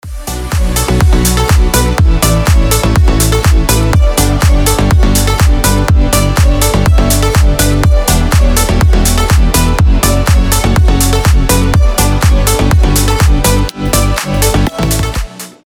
• Качество: 320, Stereo
громкие
EDM
Club House
без слов
future house
Громкий хаус проигрыш